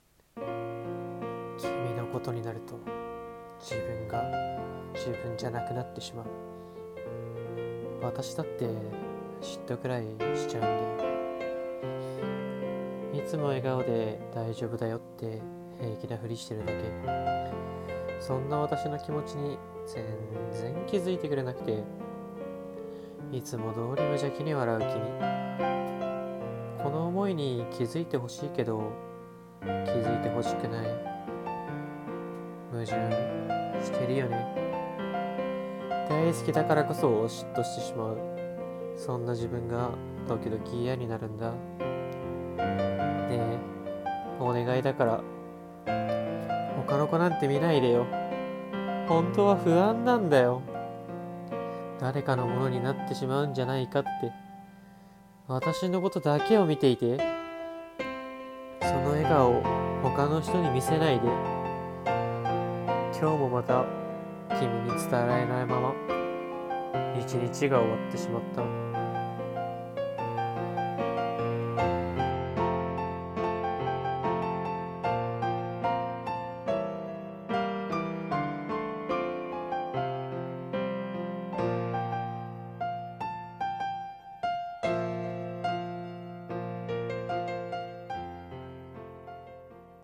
声劇/朗読【嫉妬 自分だけに笑顔を見せて…】